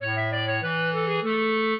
clarinet
minuet3-6.wav